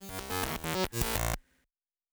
Glitch 1_07.wav